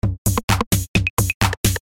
描述：立体声卡通人物的声音，往上走，用电容式麦克风和麦洛特隆录制
标签： 卡通 FX SoundEffect中 向上 向上 古怪
声道立体声